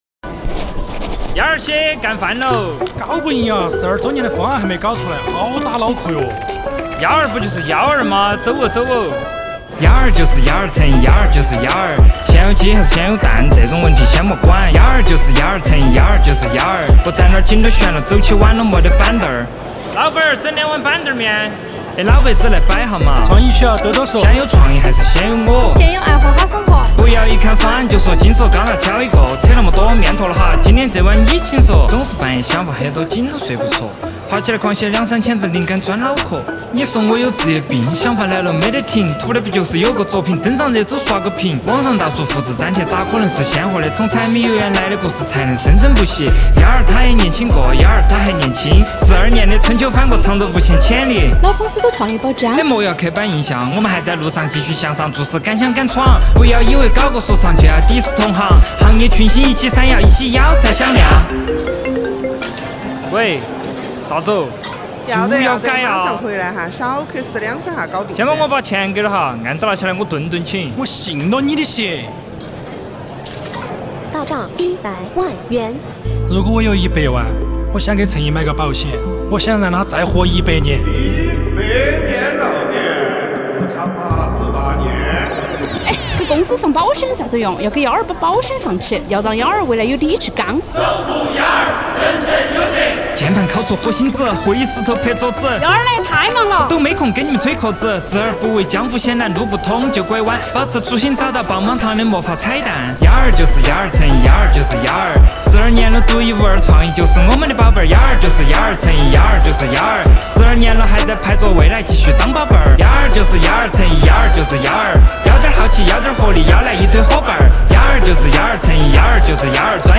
一首方言说唱：幺儿什么样？
说干就干——拉来懂行的音乐伙伴，把公司的日常切片、周边的市井声响、成都的独特韵律，通通揉进了beat里
这就是橙意十二岁想“吆”声音，它不华丽，但足够真诚；不宏大，但很接地气。